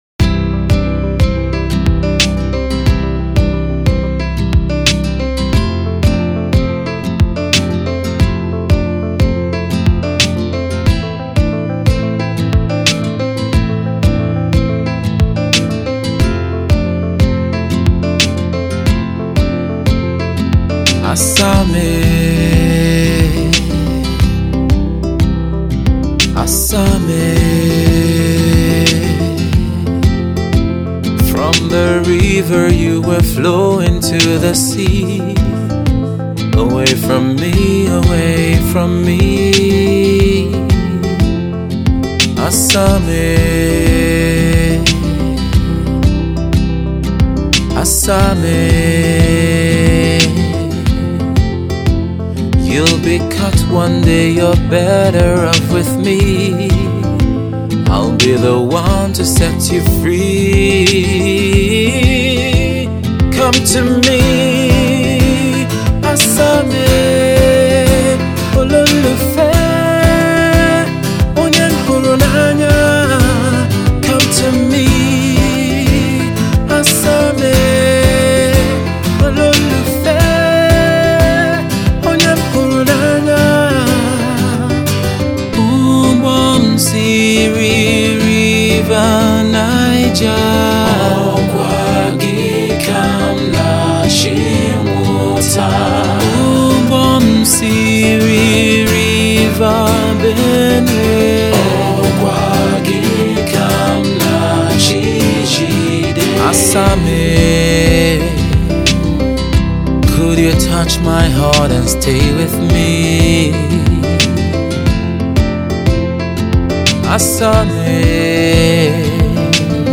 somber love tale
minimalistic format
touching ballad